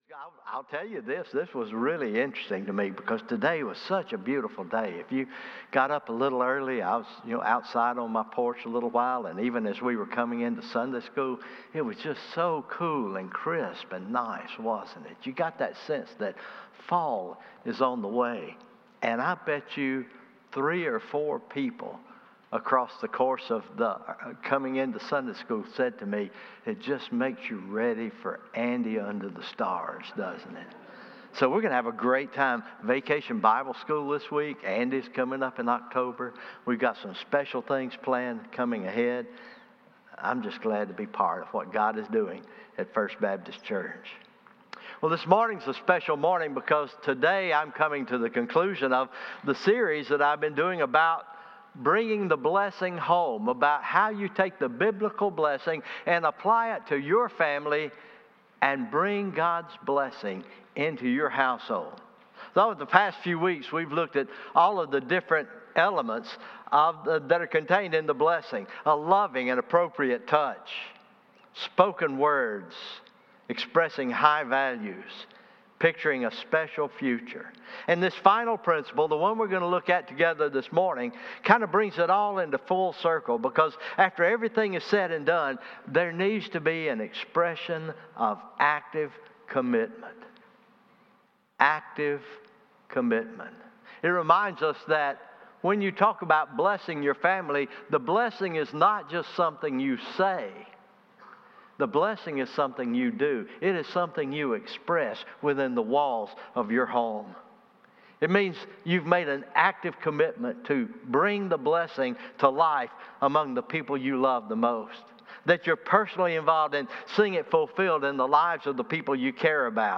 It was recorded live on 9/1/25. (Unfortunately no one joined the discussion for this topic...so it became a teaching instead) Link to the original discussion